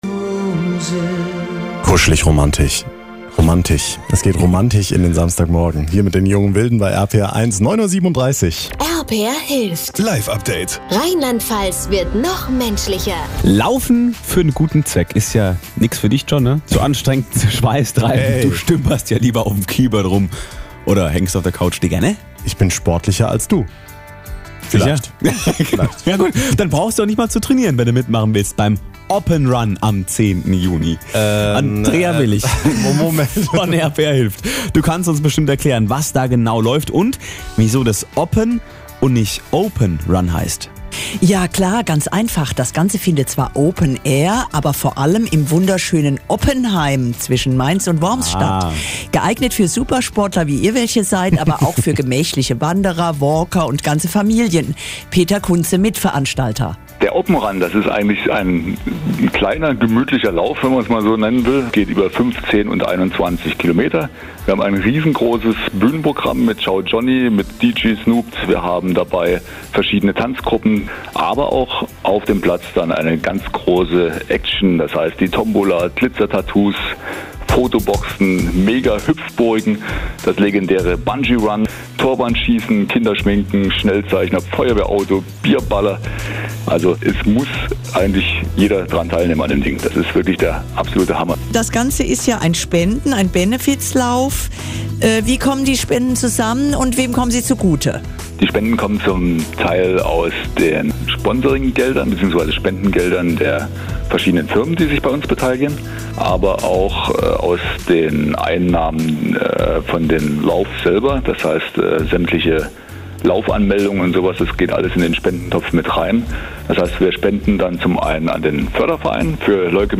Zum Reinhören ein Mitschnitt von RPR1, gesendet einen Tag vor dem Lauf – am 9.06.18: